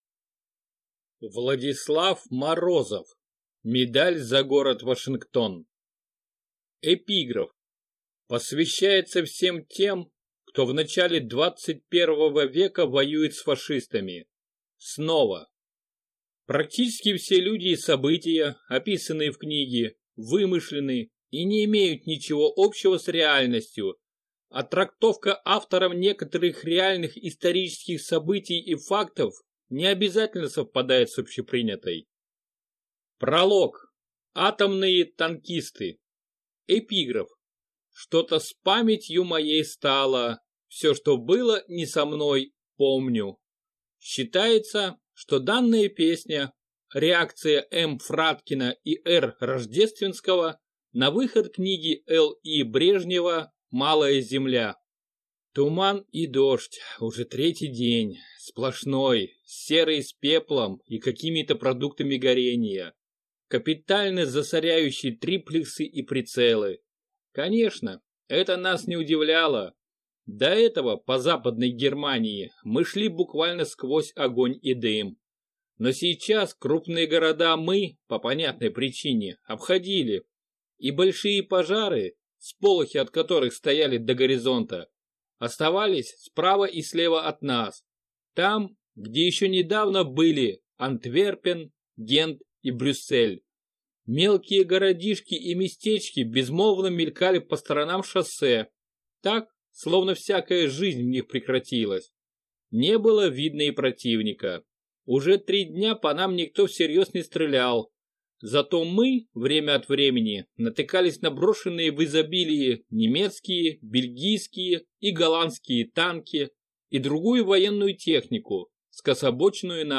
Аудиокнига Медаль за город Вашингтон | Библиотека аудиокниг
Прослушать и бесплатно скачать фрагмент аудиокниги